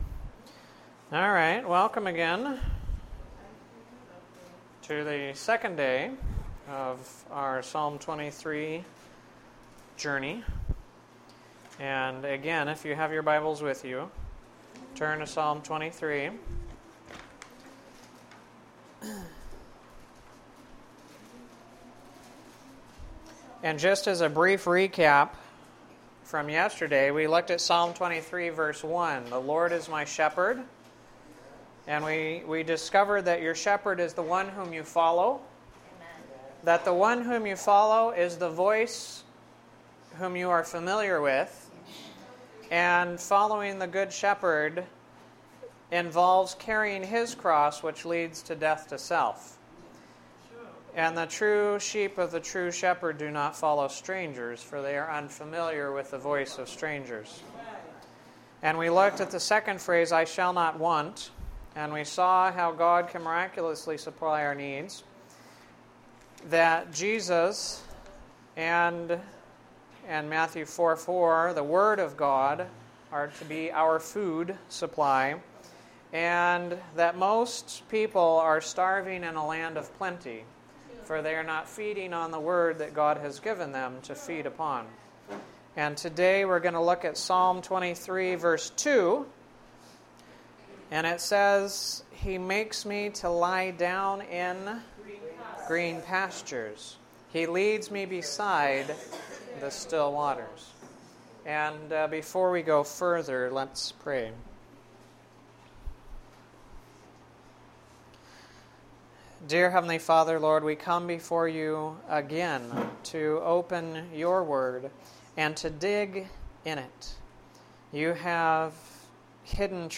This is the second of 5 presentations done at Community Hospital during the week of January 10-14, 2011. These thoughts come from Psalm 23:2.